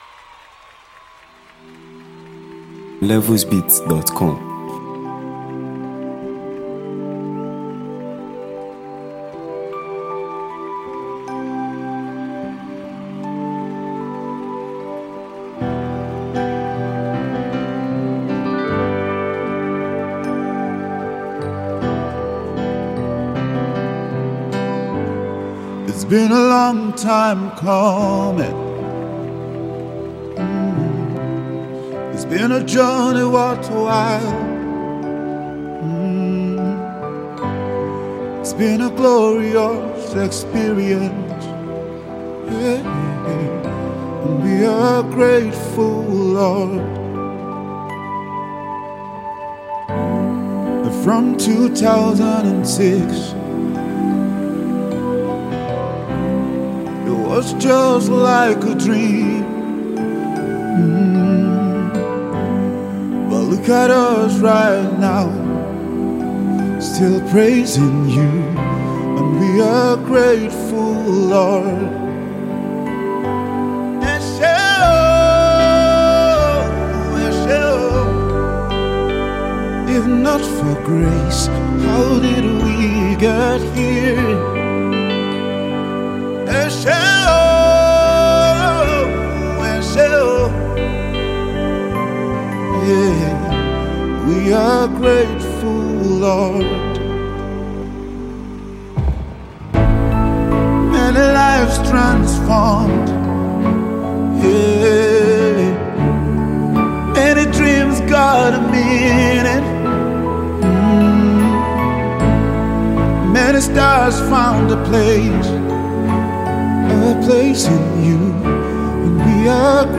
powerful and soul-lifting worship song